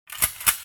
shotgunCock.wav